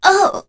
Kara-Vox_Damage_02.wav